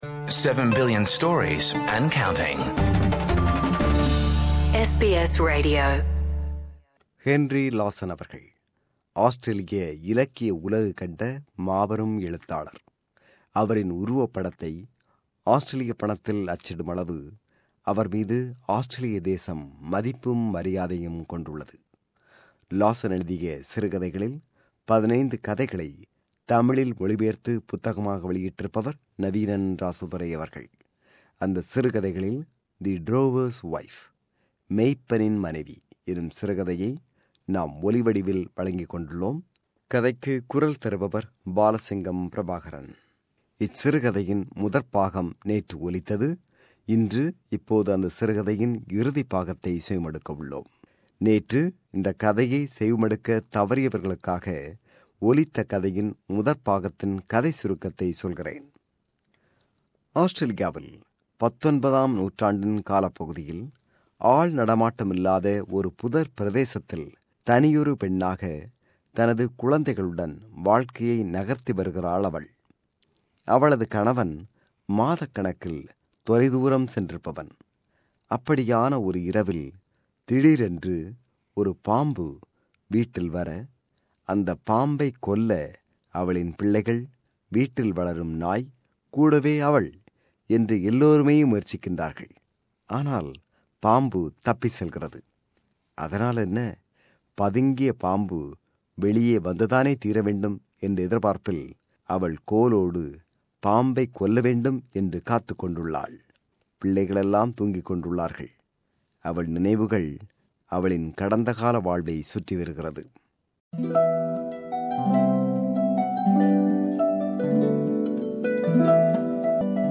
அந்தச் சிறுகதைகளில் "The Drover's wife" எனும் சிறுகதையை நாம் இங்கே ஒலிவடிவில் தருகிறோம். Henry Lawson "The Drover's wife" எனும் எனும் சிறுகதையின் நிறைவுப்பாகம்.